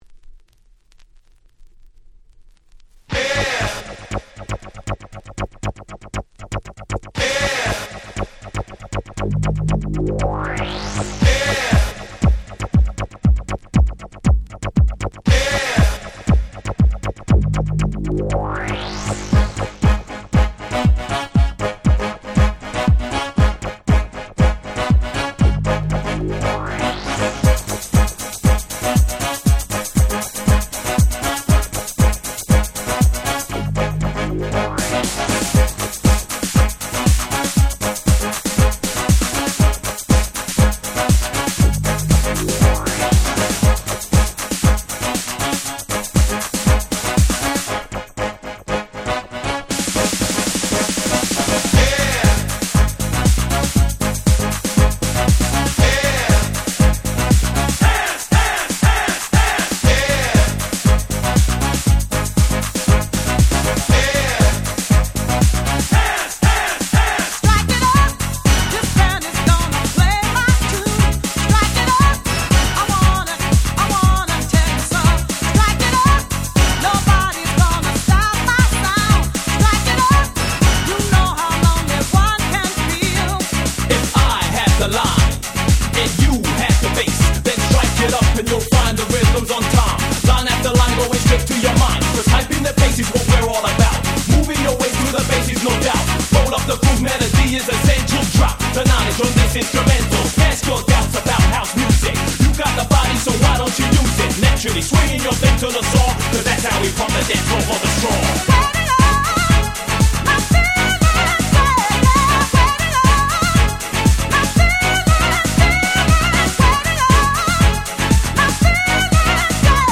91' Super Hit Dance Music !!
世界的ヒットDance Pop !!